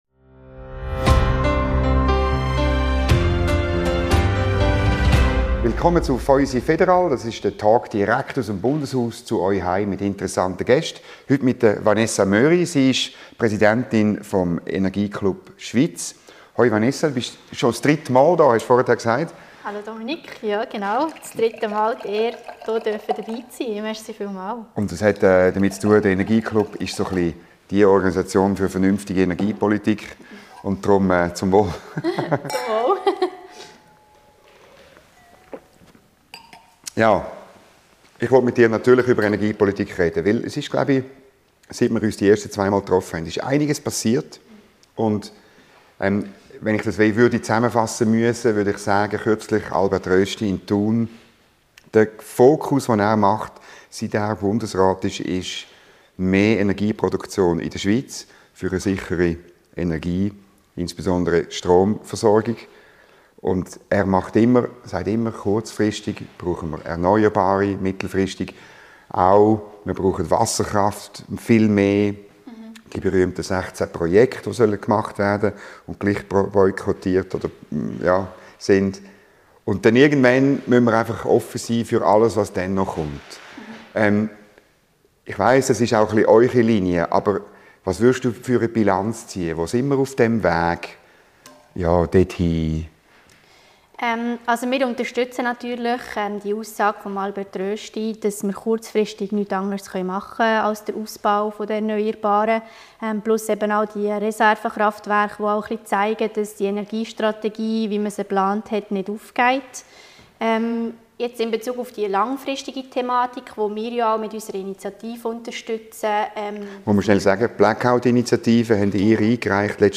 Ein Gespräch wie kein anderes: Schweizer Politik und (meist) eine Flasche Wein.